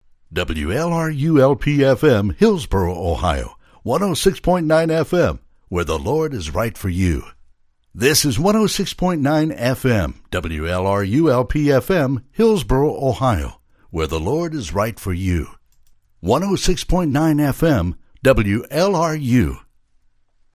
Male
My voice ranges from deep Bass to Baritone.
Radio / TV Imaging
Radio Id Pkg
Words that describe my voice are Deep, Southern, Cowboy.